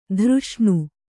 ♪ dhřṣṇu